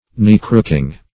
Search Result for " knee-crooking" : The Collaborative International Dictionary of English v.0.48: Knee-crooking \Knee"-crook`ing\, a. Obsequious; fawning; cringing.
knee-crooking.mp3